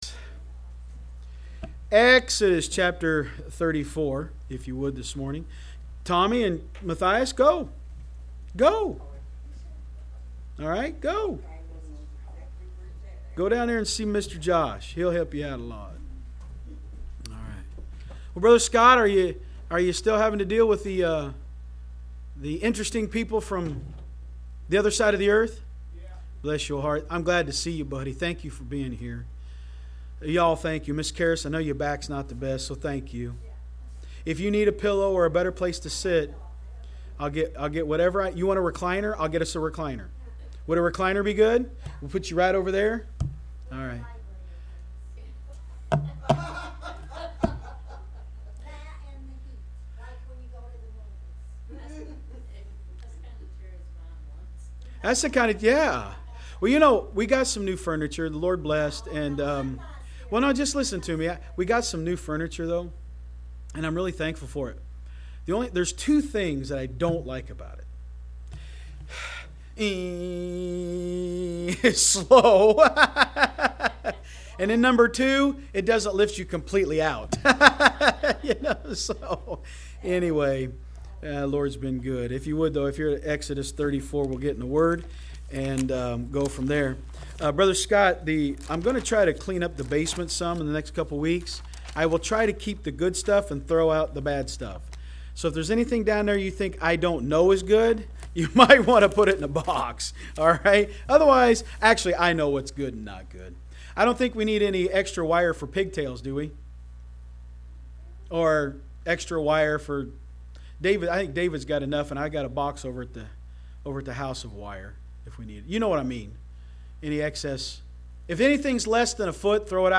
Online Sermons – Walker Baptist Church
From Series: "Sunday School"